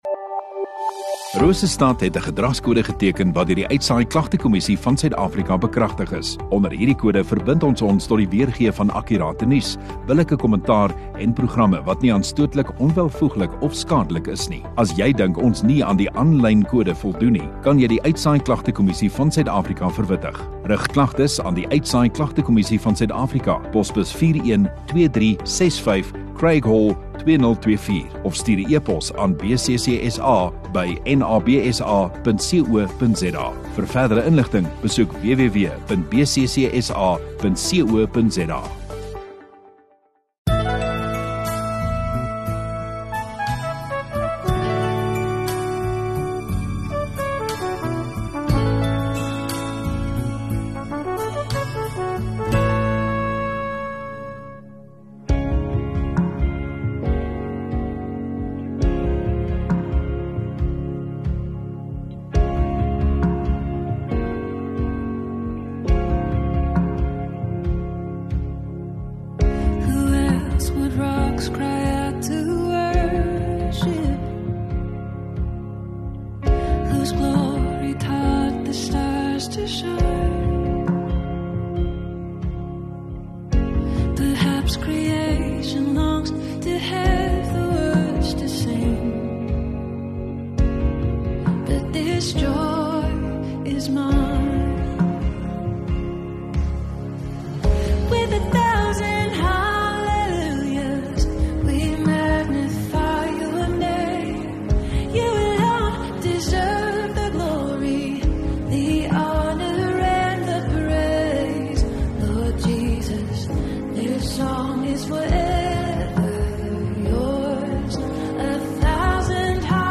9 Mar Sondagaand Erediens